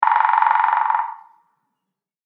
自然・動物 （58件）
アオゲラのドラミング.mp3